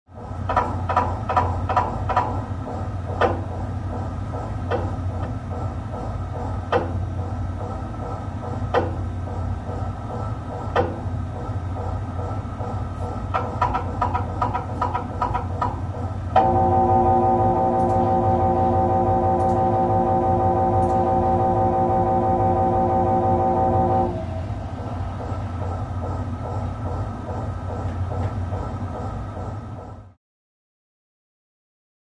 MRI; Scan In Progress, Various Knocks, Low Beeps, Machine Drone In Background